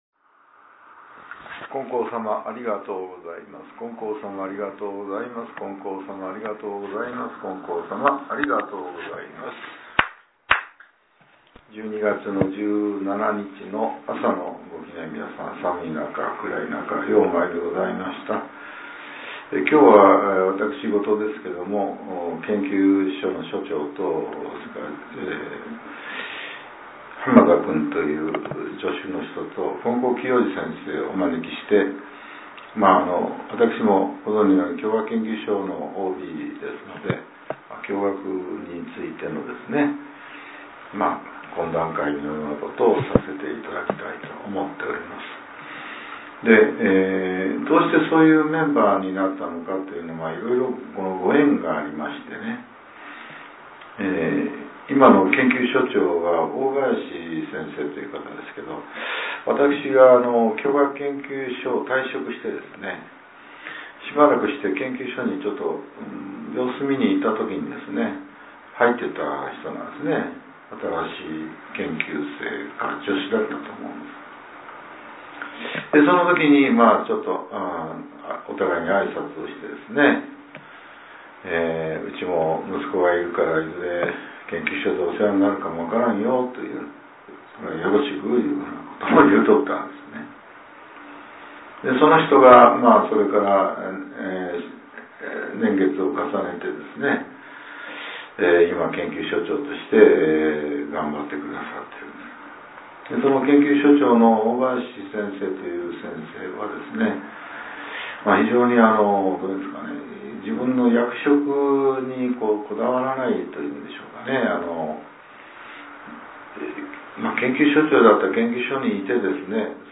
令和７年１２月１７日（朝）のお話が、音声ブログとして更新させれています。